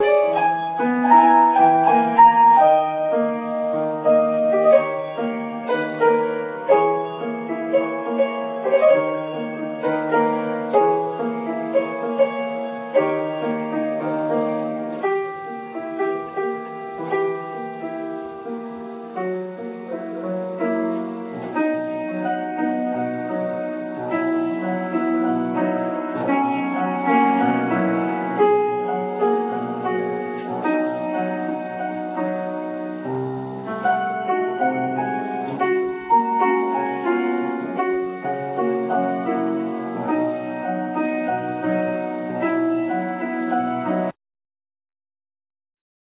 Concert
of the students of the master-class for piano
Piano